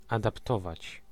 Ääntäminen
France: IPA: [a.ʁɑ̃.ʒe]